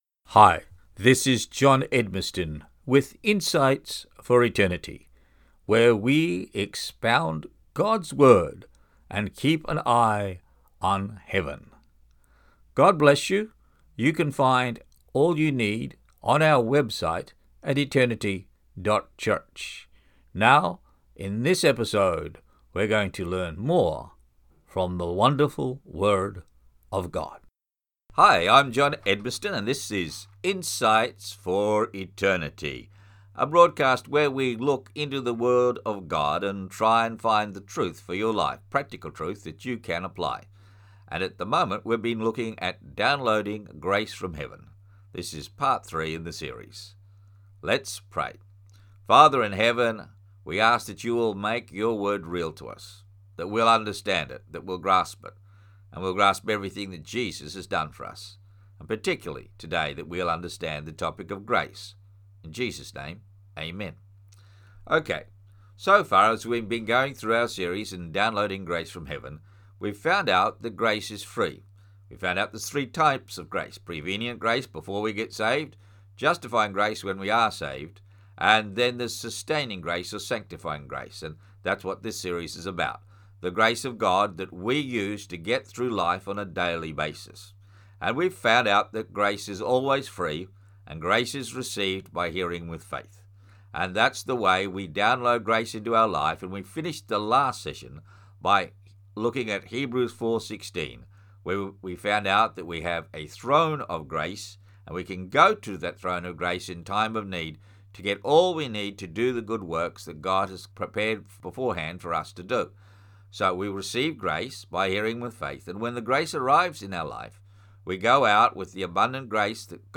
Insights for Eternity is a Christian bible-teaching radio program that broadcasts across Africa twice a week.